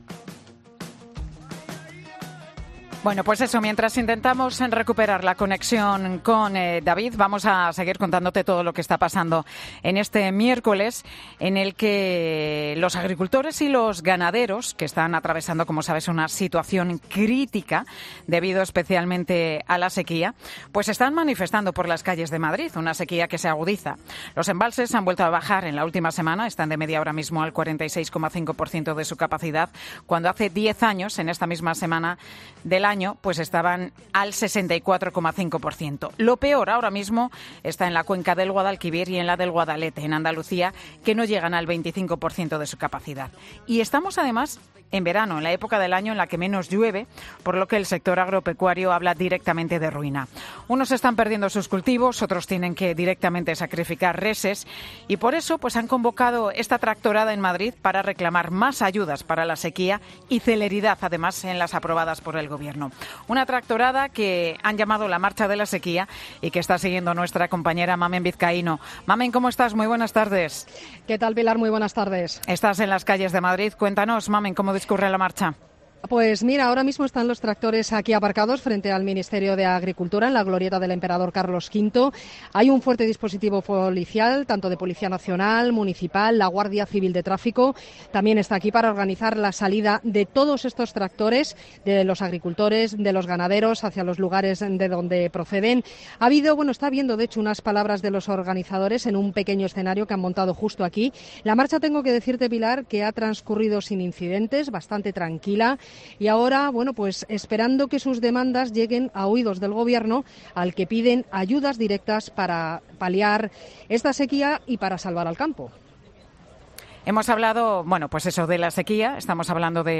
entrevista para Mediodía COPE a varios agricultores en la tractorada de Madrid